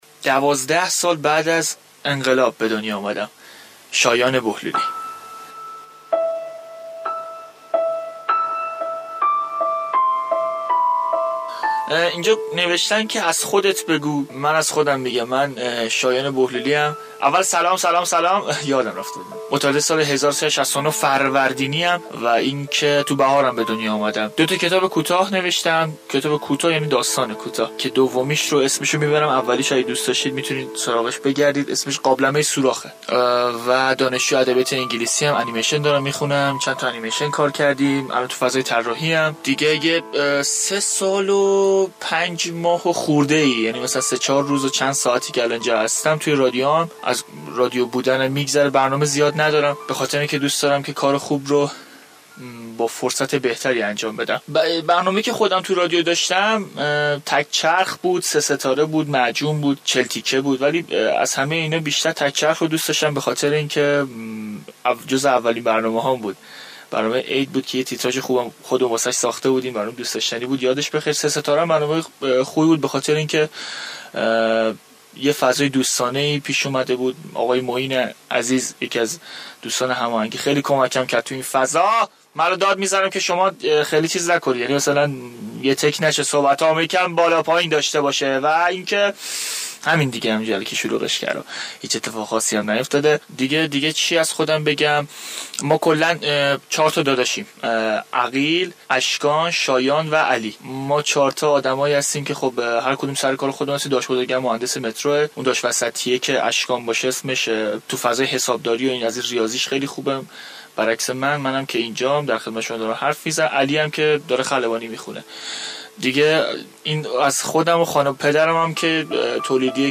فایل صوتی گفتگو